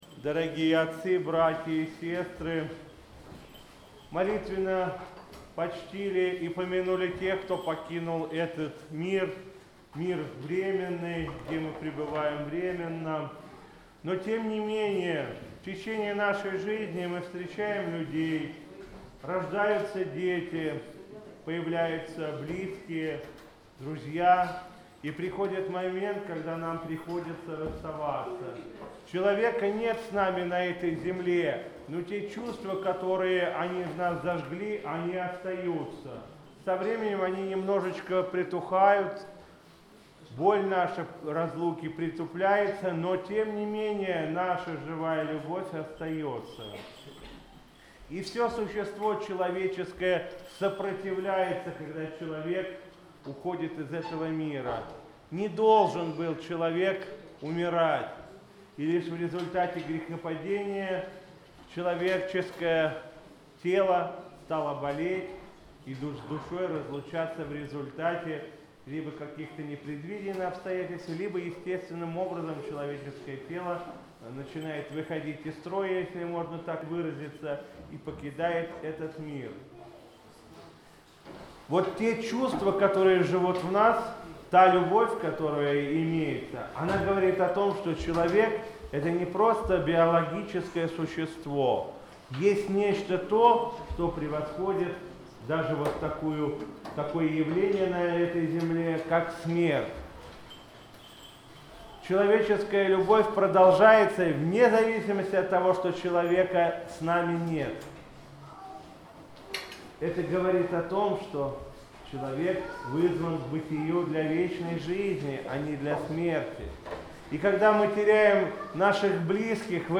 По окончании Литургии владыка Игнатий совершил панихиду по всем от века усопшим православным христианам, а затем правящий архиерей обратился к присутствующим со словом назидания.